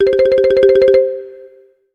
ringtone.wav